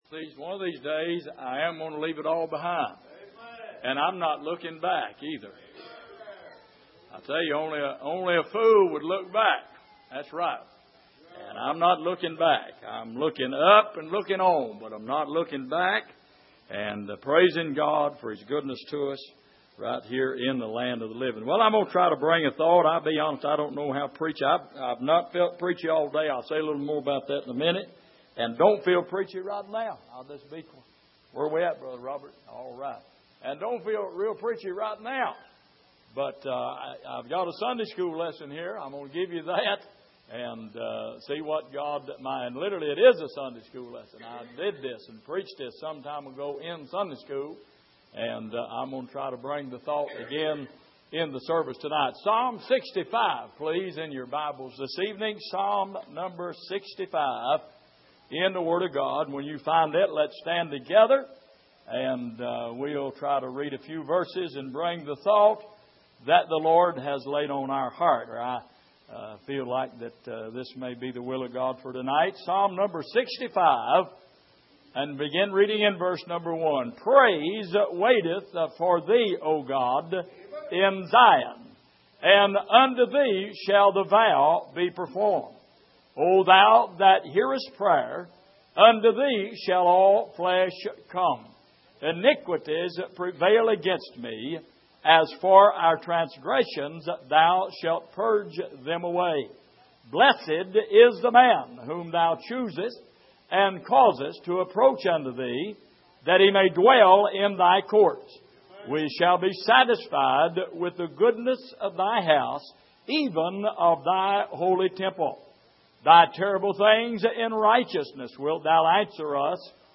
Passage: Psalm 65:1-7 Service: Midweek